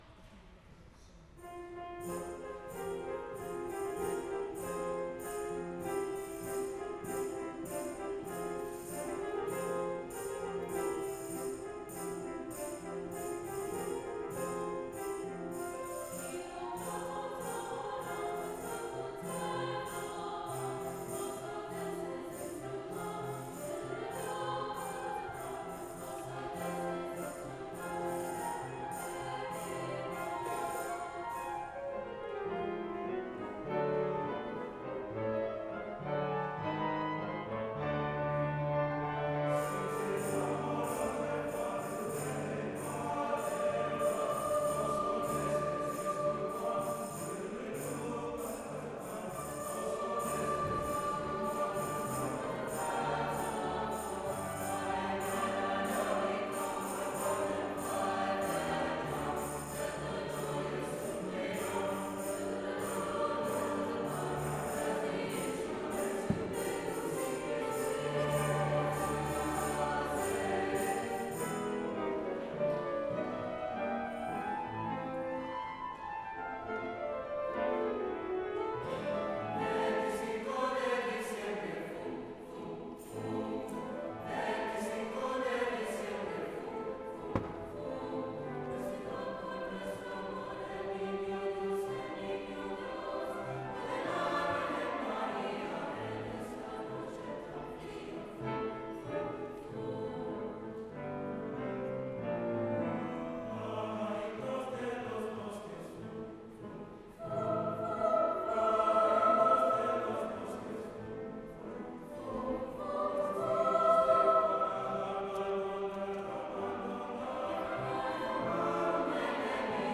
Excerpts from A Symphony of Carols by Randol A. Bass- 2018 Winter Concert